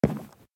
1.21.5 / assets / minecraft / sounds / step / wood3.ogg
wood3.ogg